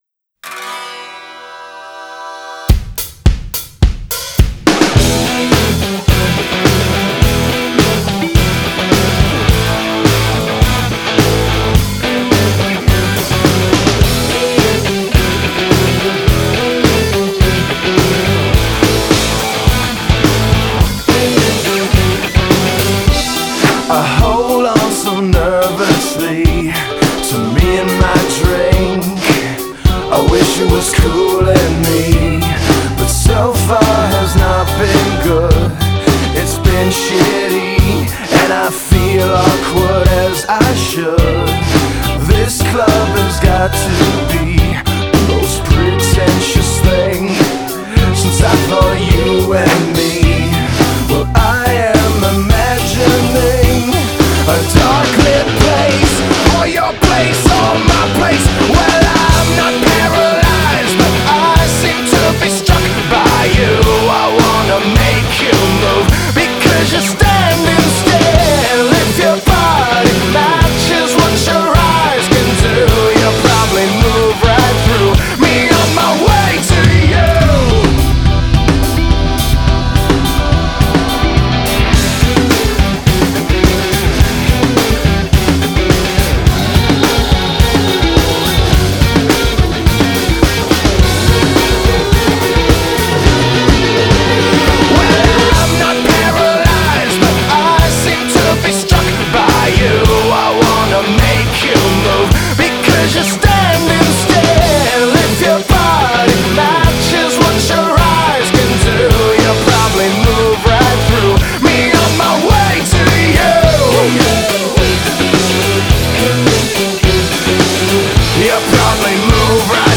BPM106--1
Audio QualityMusic Cut